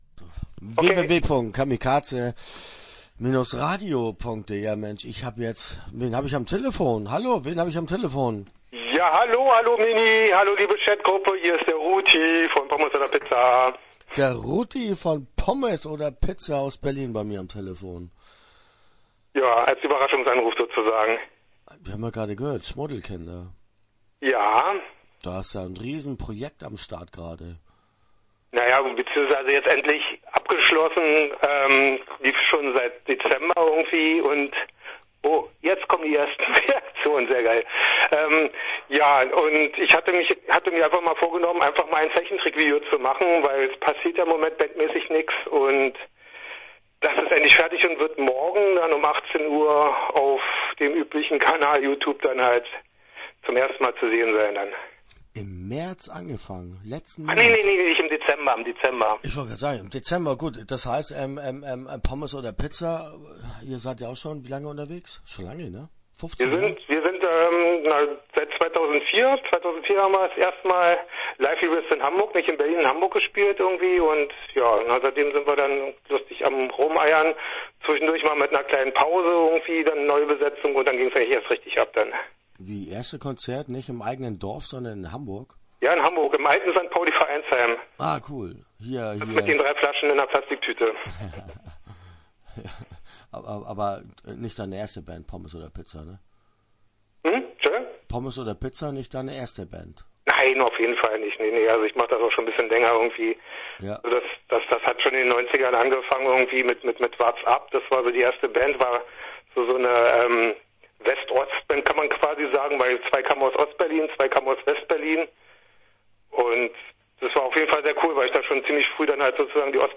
Start » Interviews » Pommes oder Pizza